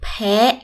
/ pae